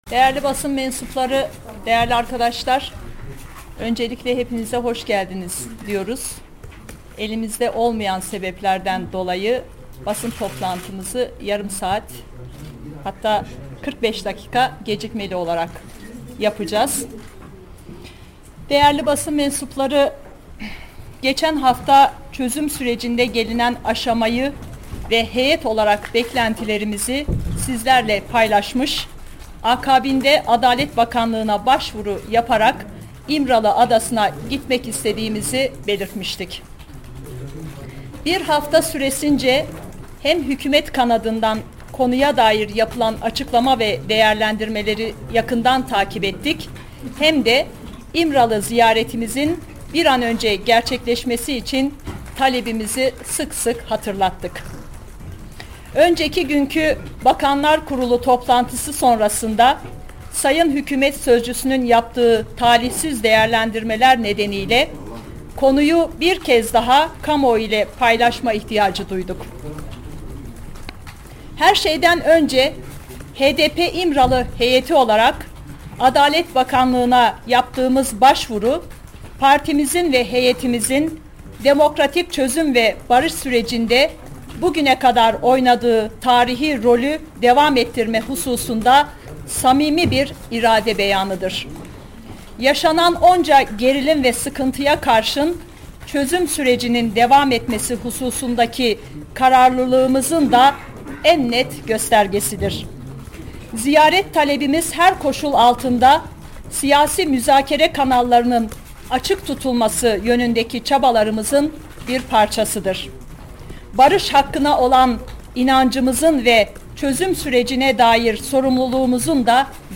HDP Heyeti Basın Toplantısı